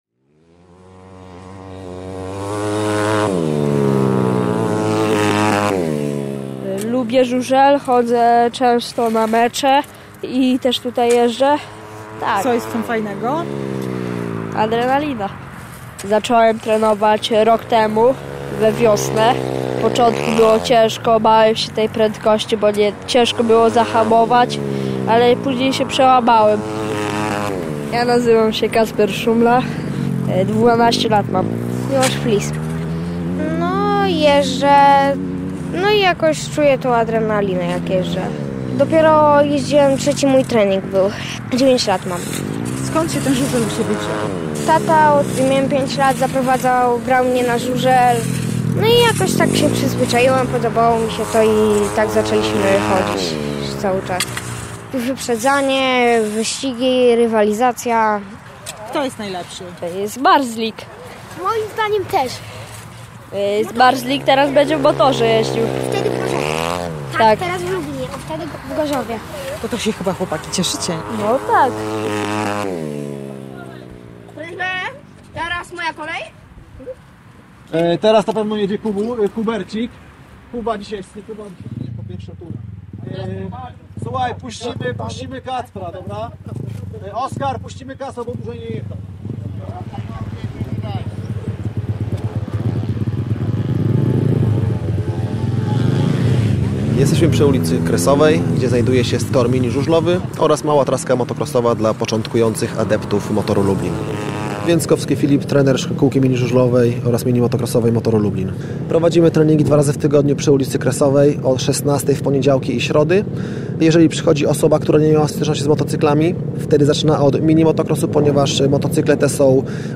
młodzi adepci żużla i ich rodzice mówią o funkcjonowaniu szkółki żużlowej w Lublinie